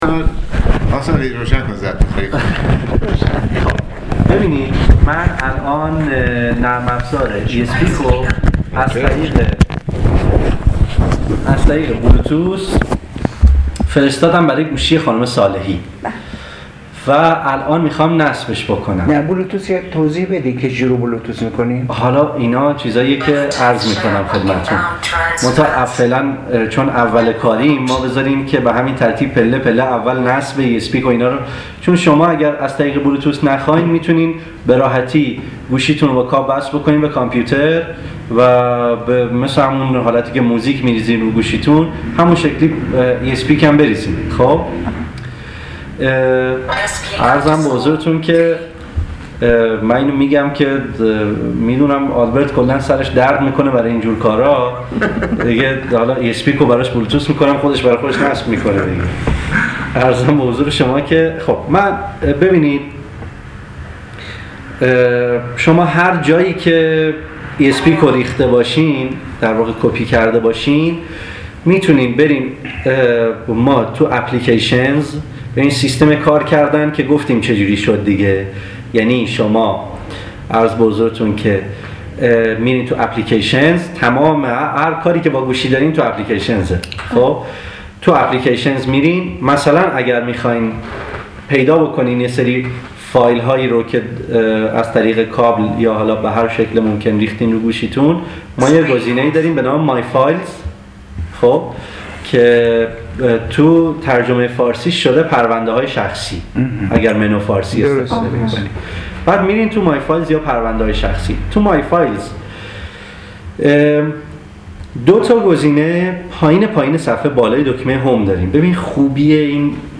کارگاه فناوری.